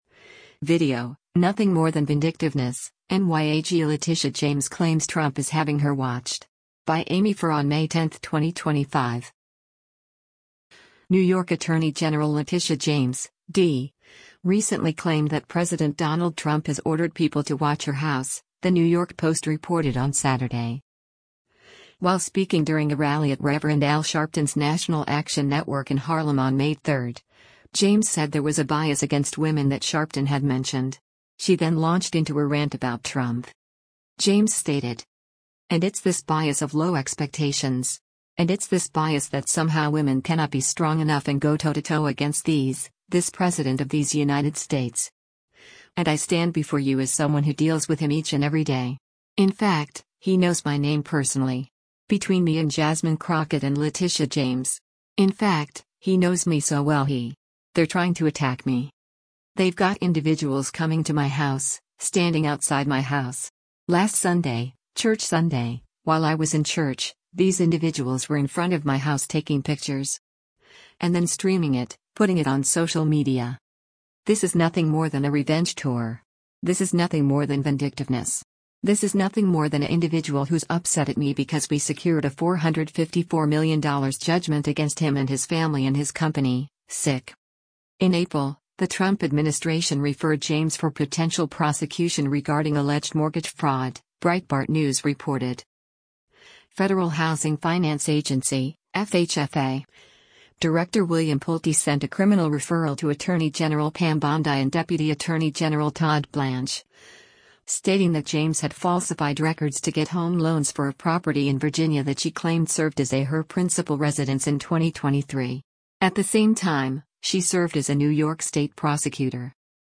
While speaking during a rally at Reverend Al Sharpton’s National Action Network in Harlem on May 3, James said there was a “bias” against women that Sharpton had mentioned.